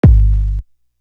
Craze Kick.wav